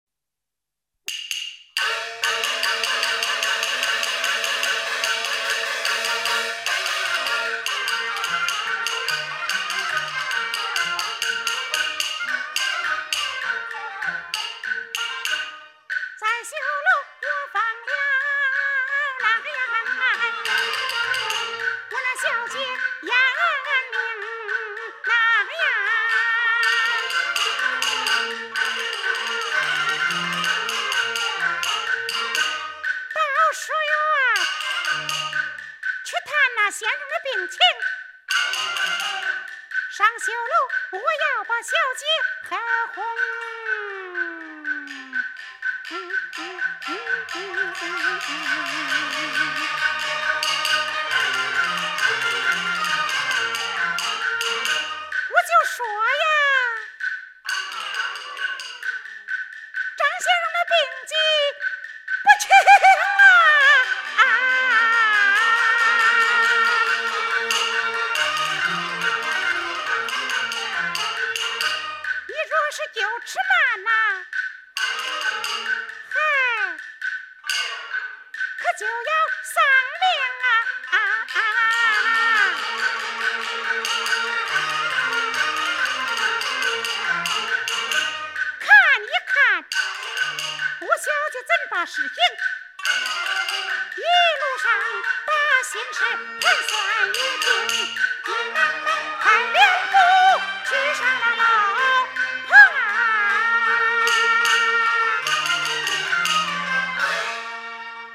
[11/8/2010]豫剧大师常香玉演唱豫剧《拷红》片断=上绣楼我要把小姐赫哄(192K MP3) 激动社区，陪你一起慢慢变老！